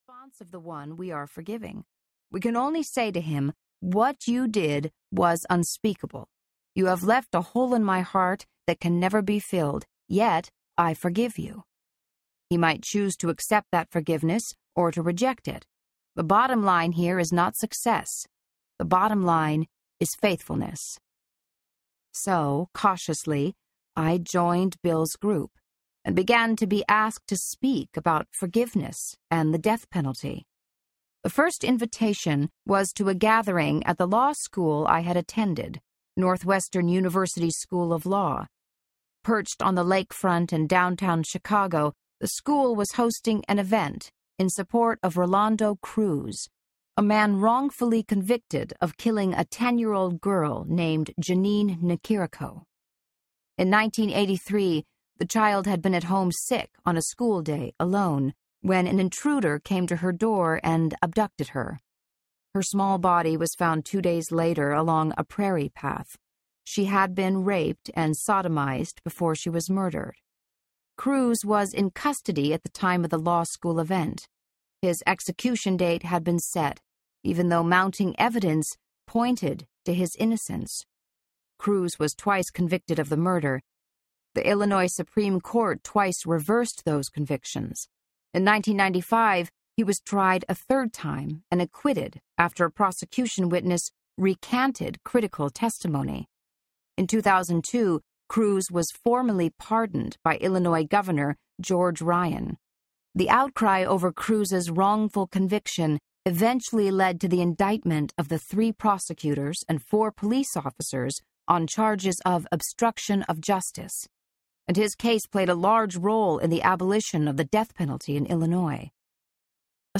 Change of Heart Audiobook